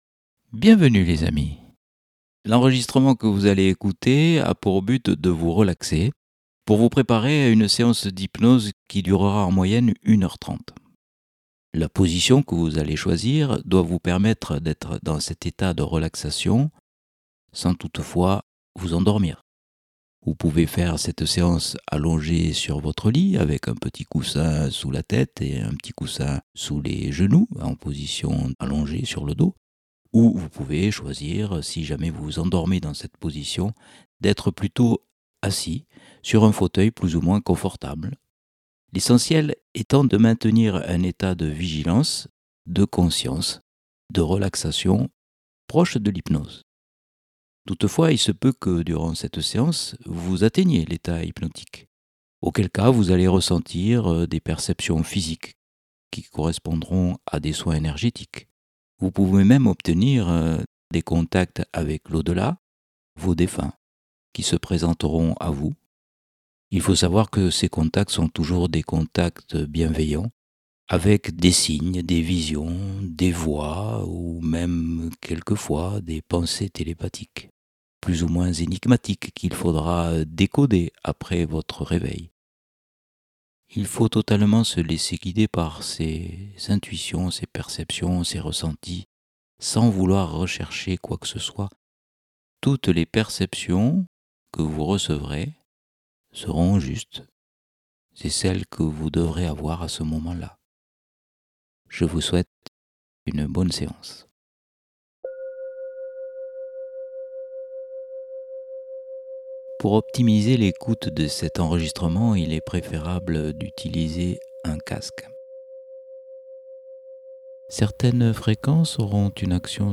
A.M.I-HYPNOSE-PREPARATION.mp3